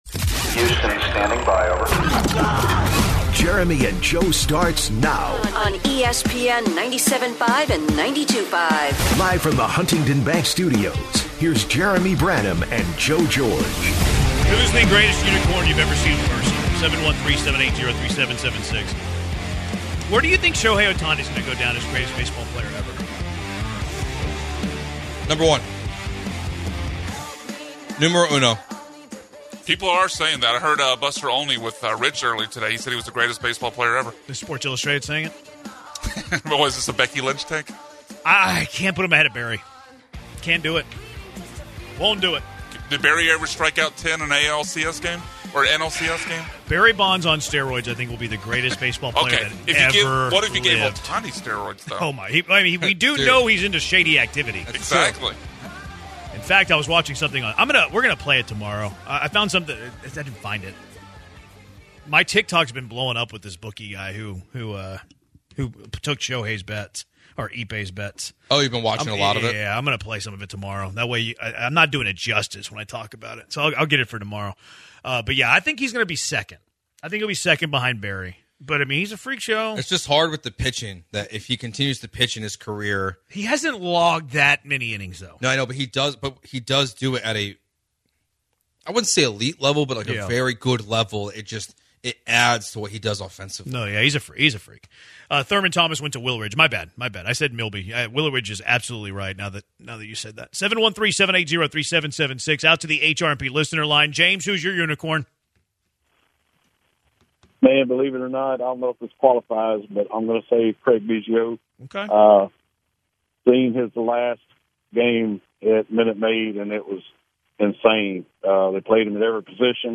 Callers give their picks for best unicorns in sports history